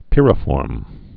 (pĭrə-fôrm)